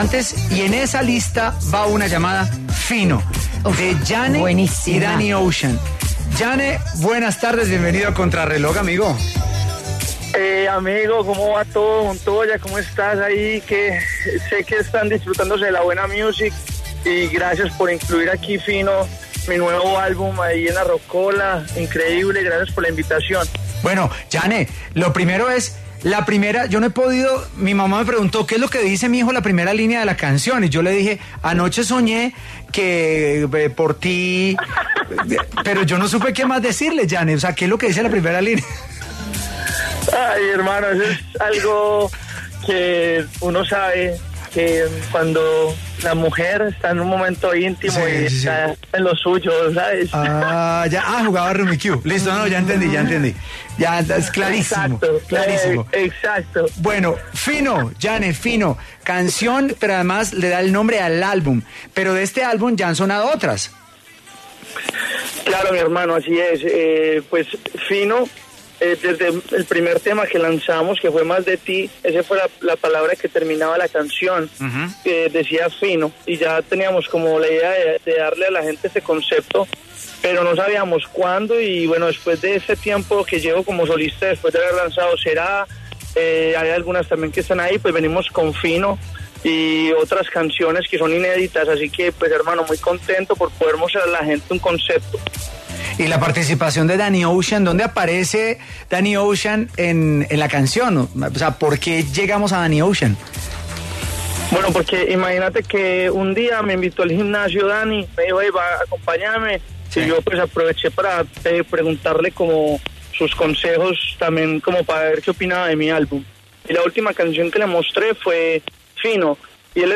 El cantante colombiano Llane habló a Contrarreloj y dio los detalles de su más reciente colaboración junto a Danny Ocean, ‘Fino’.